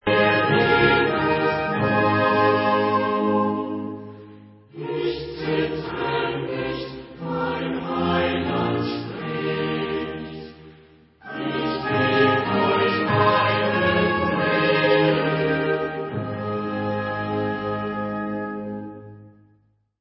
Chorale